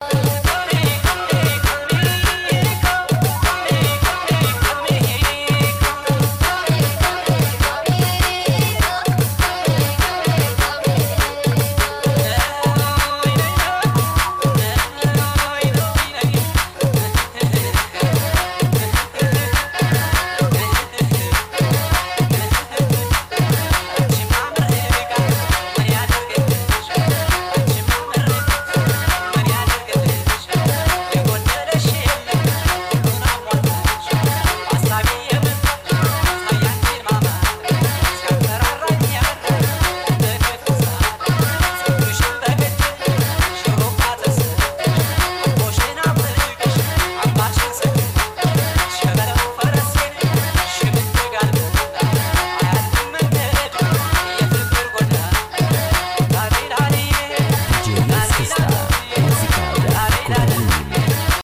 Music From The horn of Africa
ethiopian-music.mp3